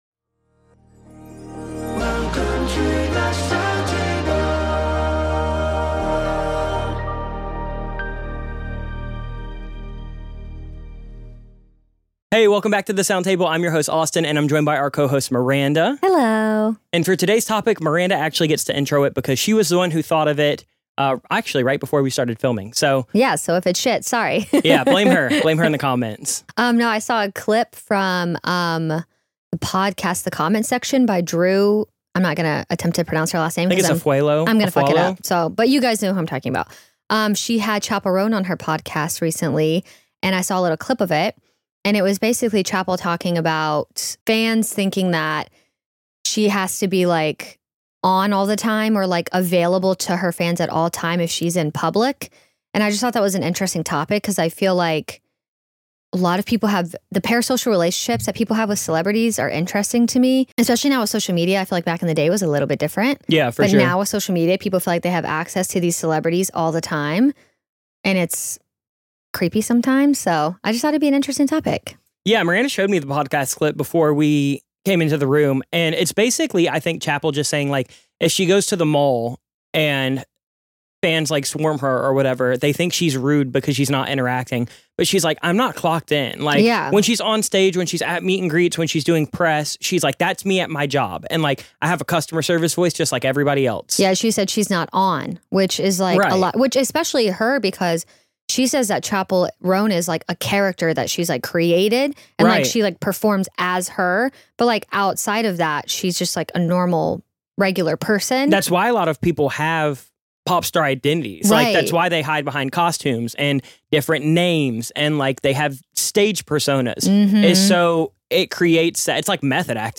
About The Sound Table: Welcome to The Sound Table, where we have chats and conversations with creative people.